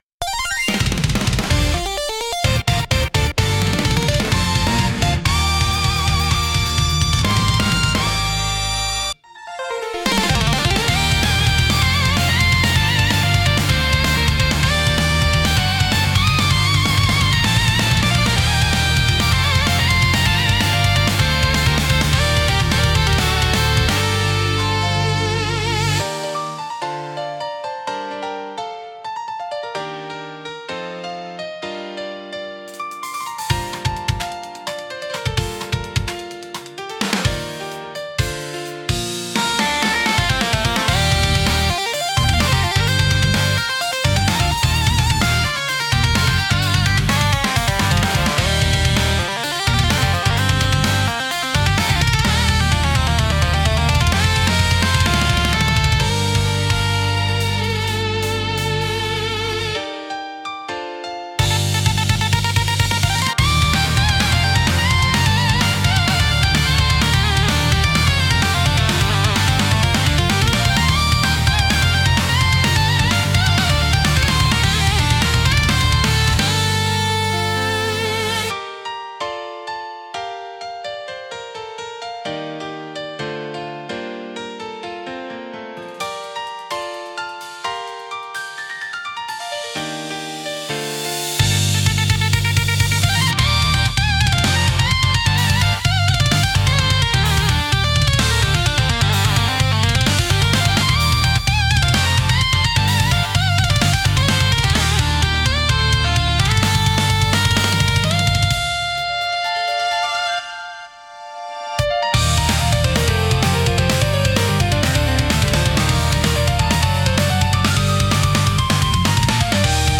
テンポの速いリズムと洗練されたサウンドで、スリルと爽快感を演出し、没入感を促進します。